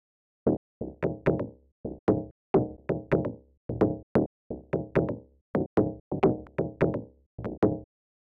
何もしてない状態はこんな音です。